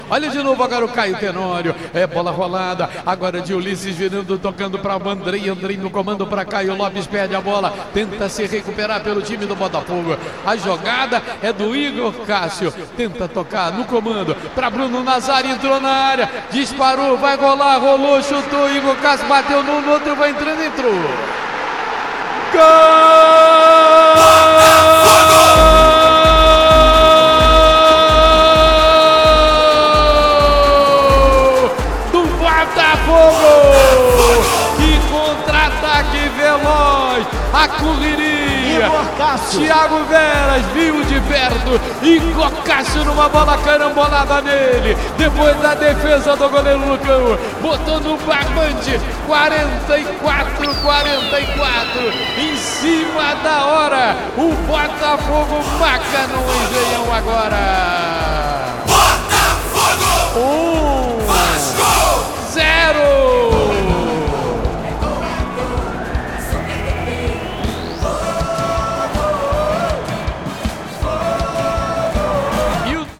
Ouça o gol da vitória do Botafogo sobre o Vasco na voz de José Carlos Araújo